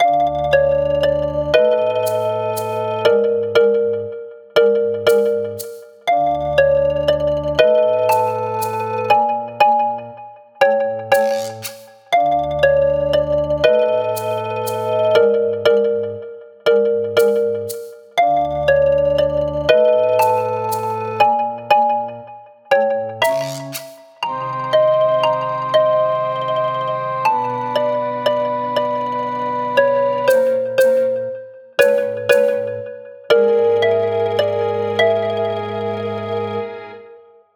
暗い楽曲
【イメージ】あやしい森 など